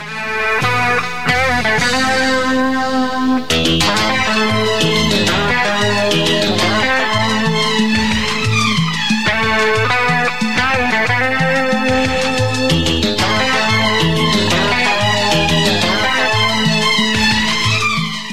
Category: Guitar Ringtones